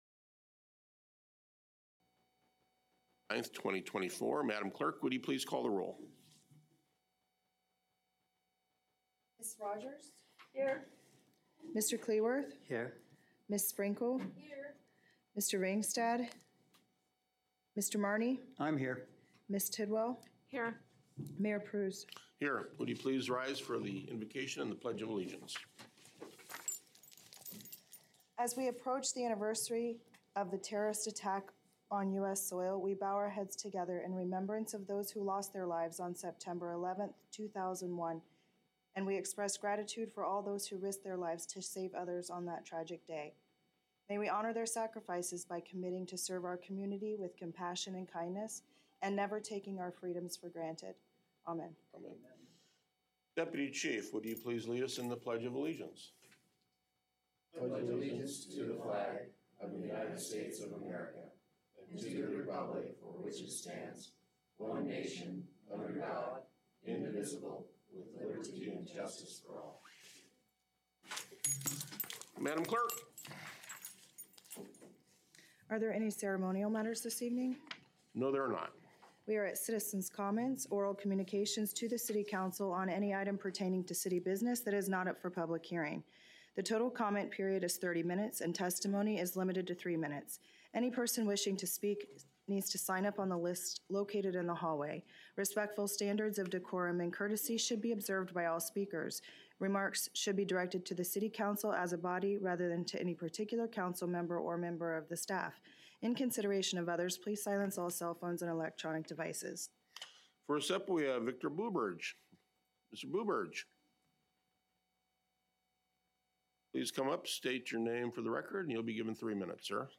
Regular City Council Meeting